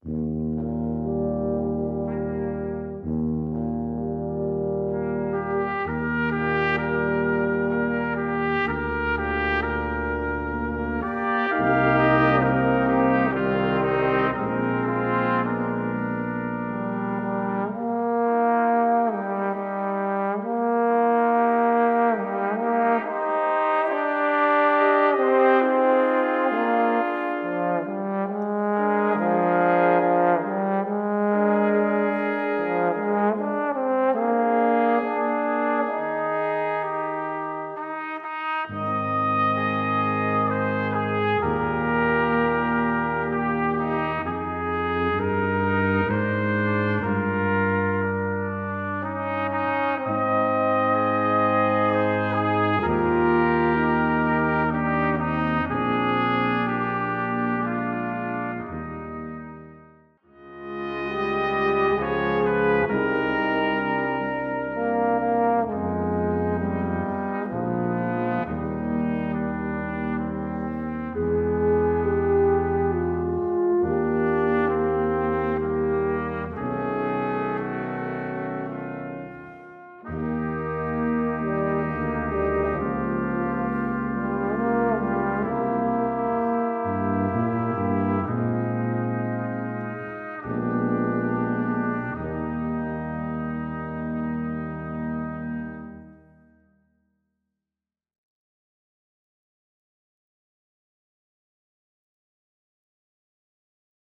Gattung: Für Blechbläserquintett
Besetzung: Ensemblemusik für Blechbläser-Quintett